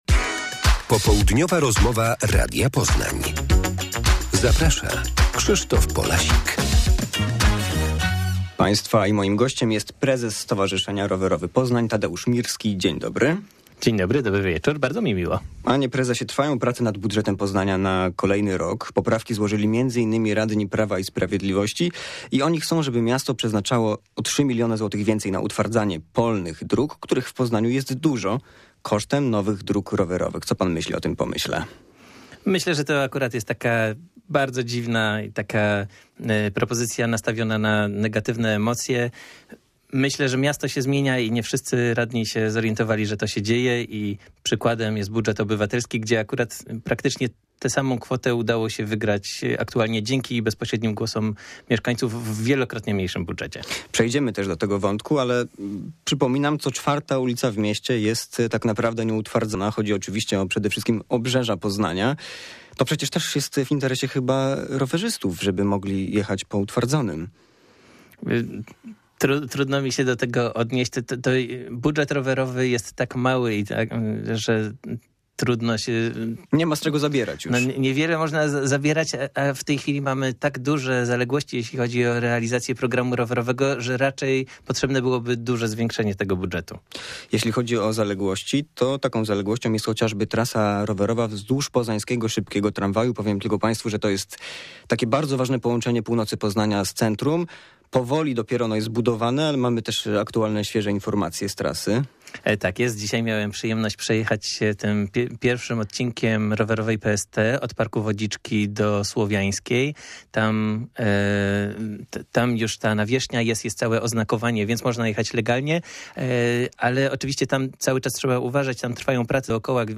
Popołudniowa rozmowa